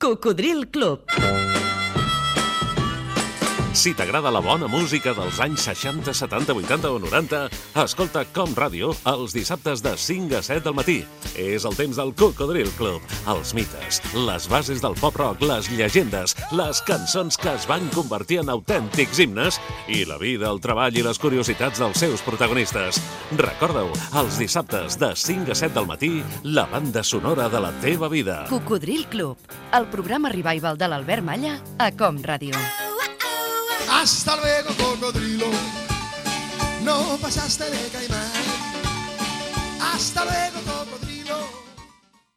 Promoció del programa
Musical